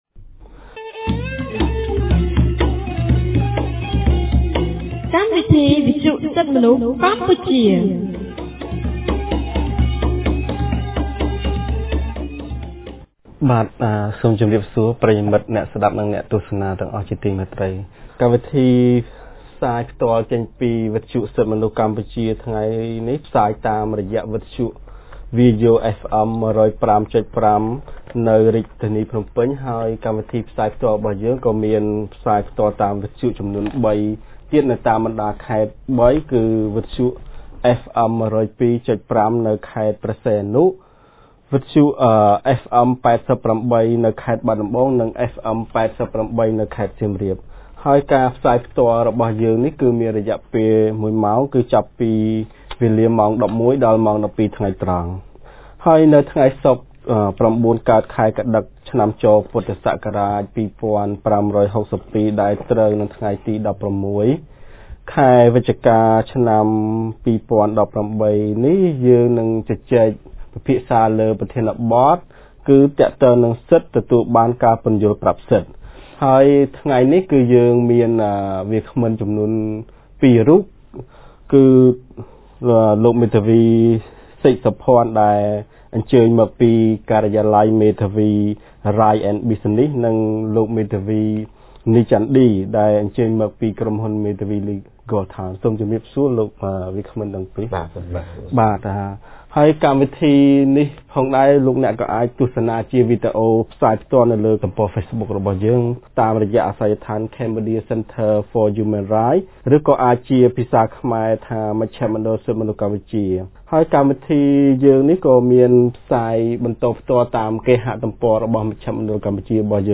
On 16 November 2018, CCHR’s Fair Trial Rights Project (FTRP) held a radio program with a topic on Right to an explanation of rights.